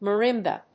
音節ma・rim・ba 発音記号・読み方
/mərímbə(米国英語), mɜ:ˈɪmbʌ(英国英語)/